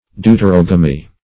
Search Result for " deuterogamy" : The Collaborative International Dictionary of English v.0.48: Deuterogamy \Deu`ter*og"a*my\, n. [Gr.